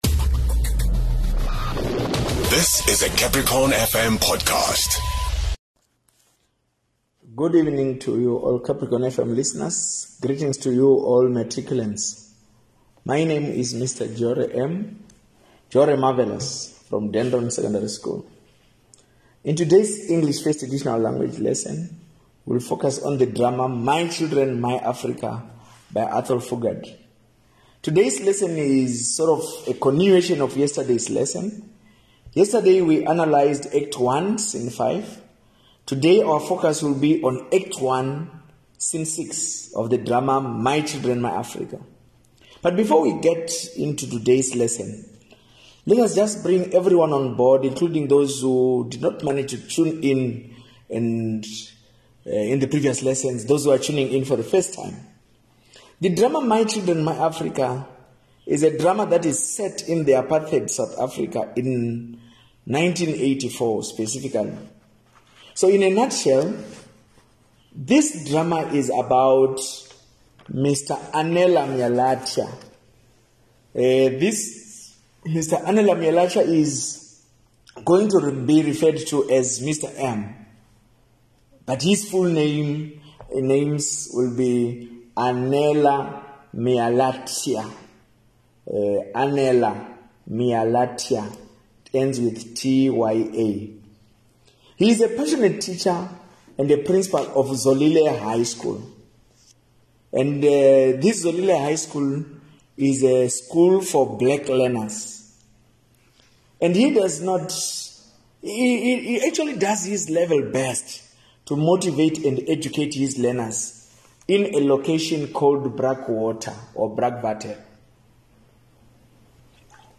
17 Nov Grade 12 Lessons